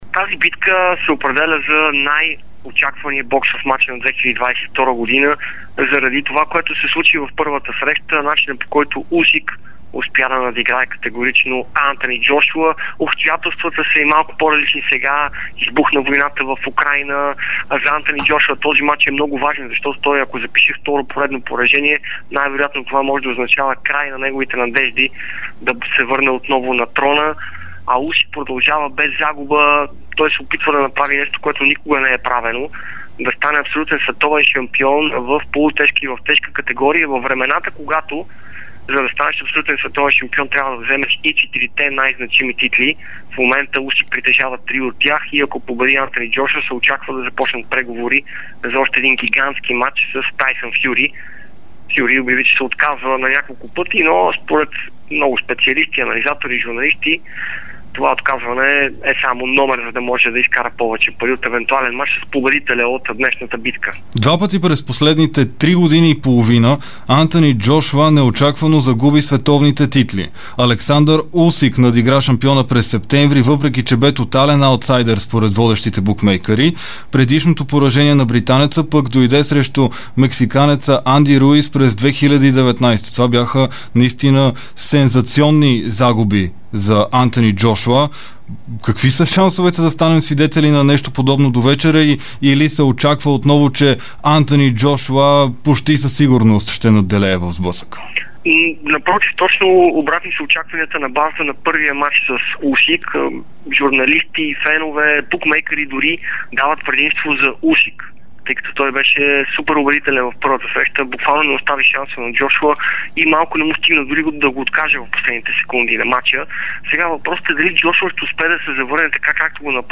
говори пред Дарик и dsport преди големия мач между Антъни Джошуа и Олександър Усик.